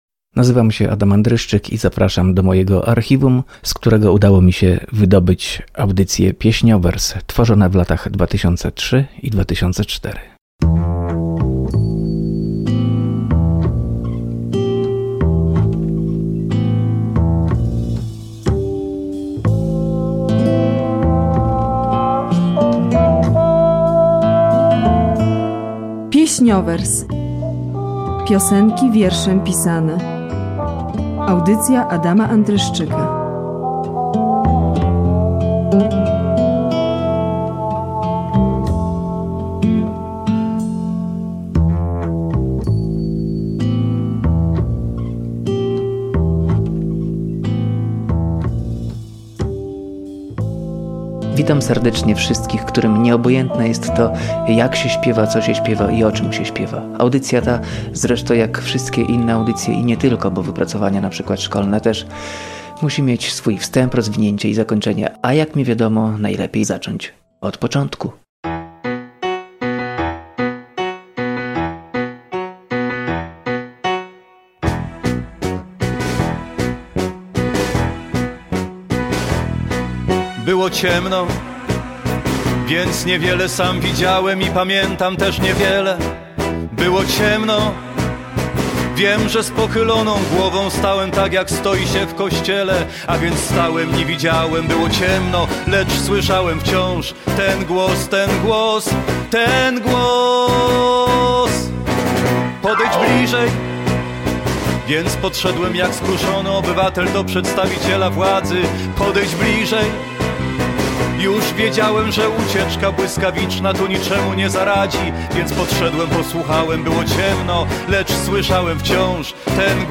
Audycja poświęcona piosence literackiej, tworzona w latach 2003-2004 dla Radia Olsztyn. W latach 2021-2022 powtarzana w Radio Danielka.